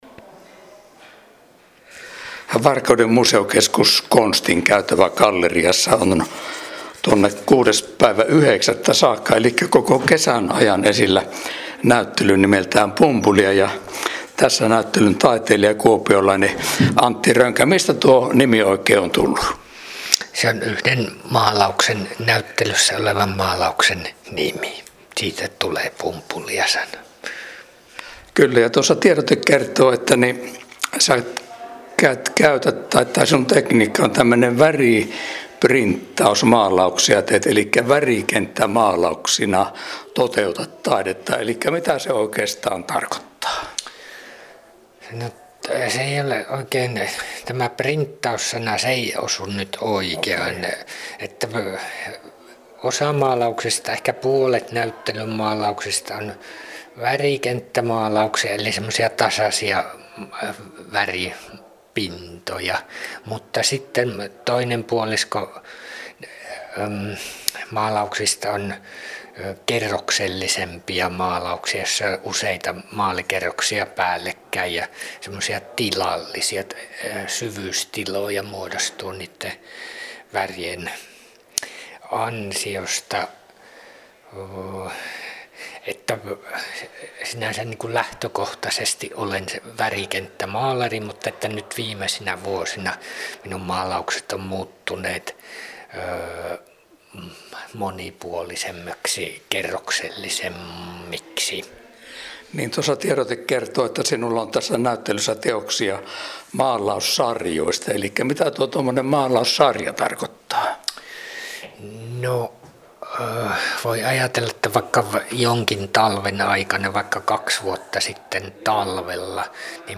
Äänihaastattelussa